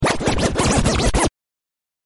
Dj Scratch Free Sound Effect Free Download
Dj Scratch Free